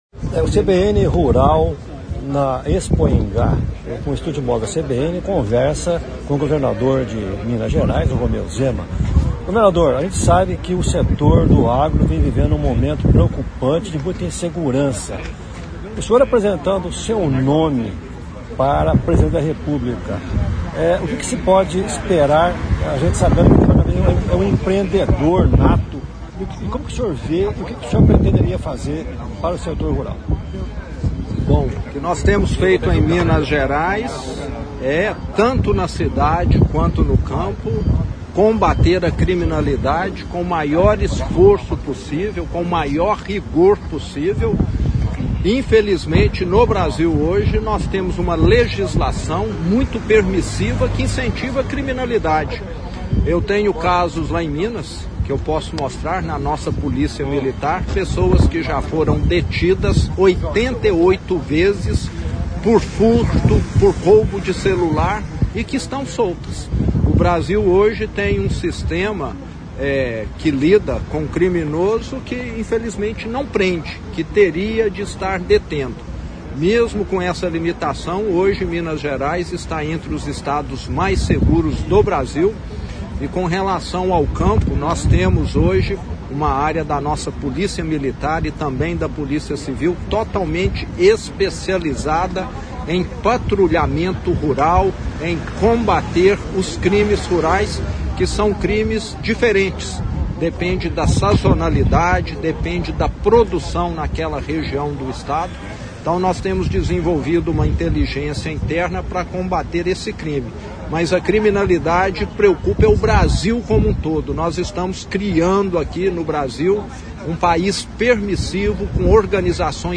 Expoingá 2025